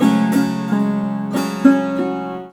SAROD3    -R.wav